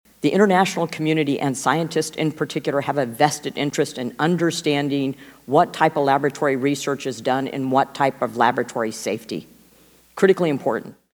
Miller-Meeks made her comments during a House Select Subcommittee on the Coronavirus Pandemic.